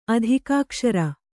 adhikākṣara